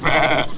Amiga 8-bit Sampled Voice
lamb.mp3